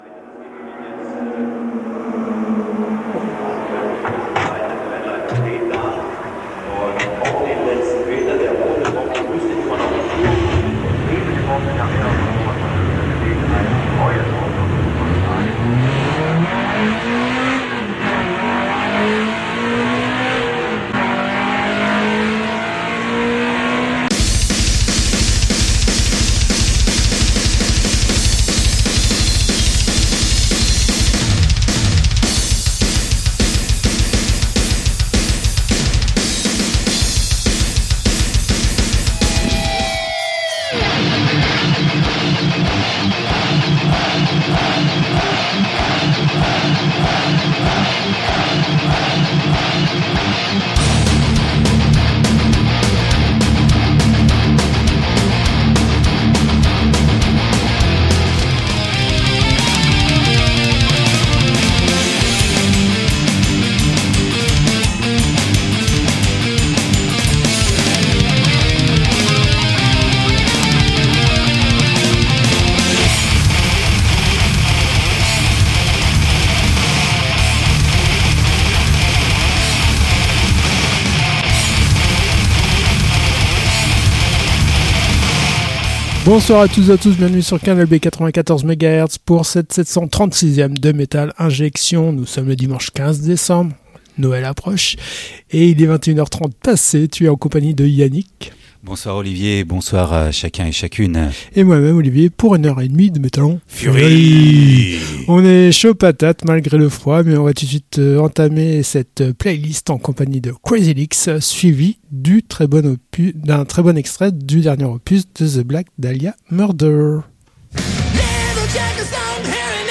Du Hard Rock au Metal extrême, Metal Injection c'est des news, des avants premières, le classique et la reprise de la semaine, l'annonce de concerts et la promotion du Metal en Bretagne.